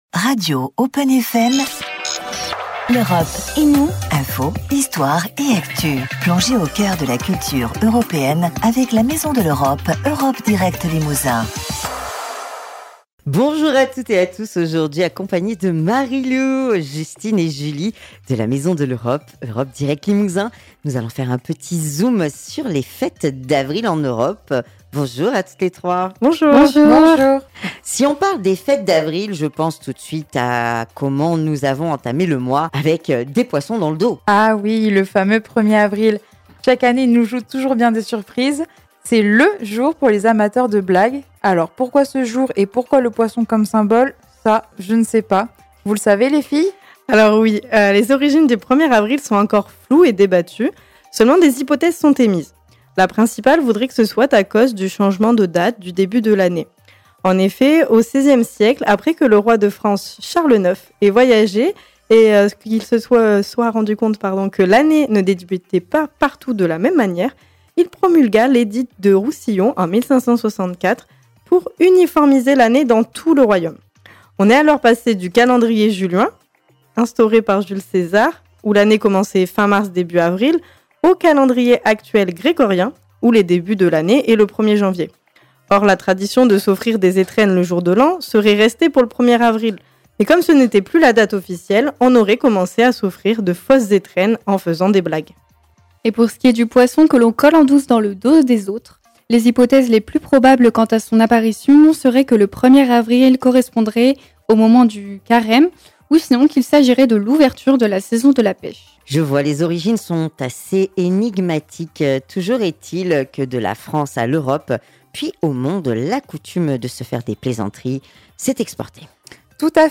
En ce mois d’avril, nos trois animatrices se sont penchées sur l’origine du fameux poisson et de la manière dont cette tradition est célébrée dans les différents pays de l’UE. Ce sujet a permis d’étendre la discussion sur les autres fêtes ou événements notables en Europe à cette période de l’année.